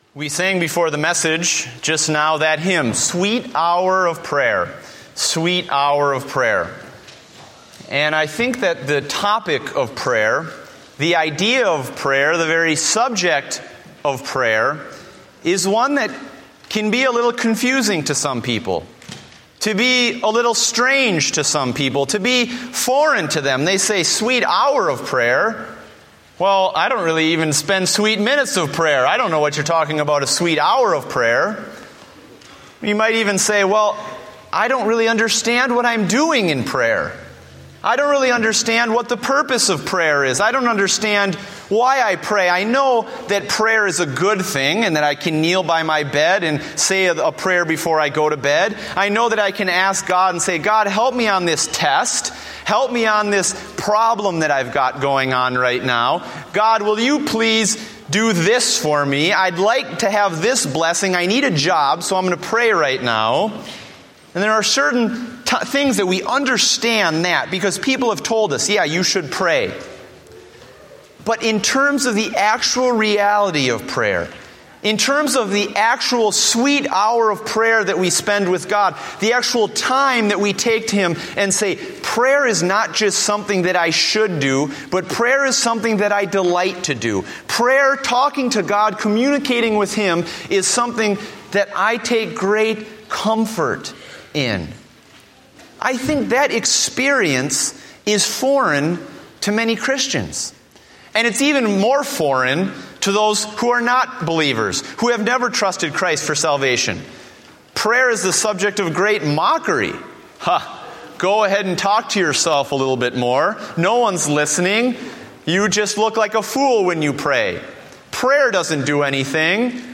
Date: April 13, 2014 (Morning Service)